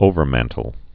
(ōvər-măntl)